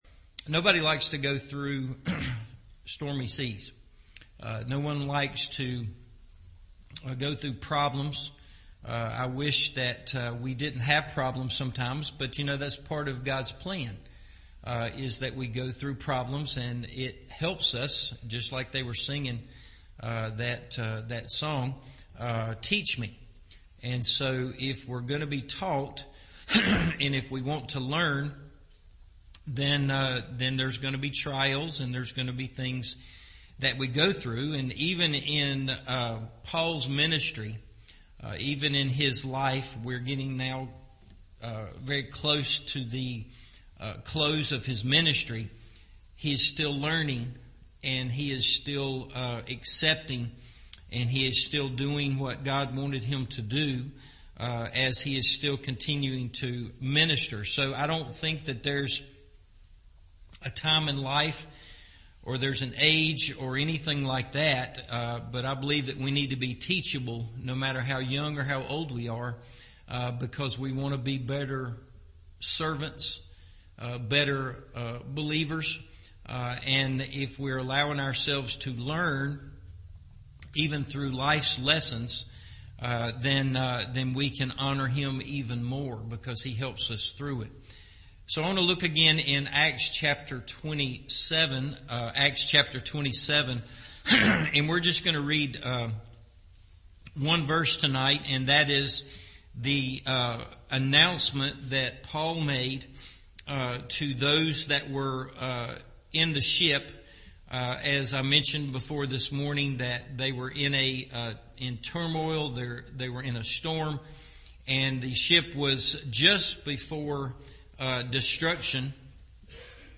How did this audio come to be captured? Four Strong Anchors for Life’s Stormy Sea – Part Two – Evening Service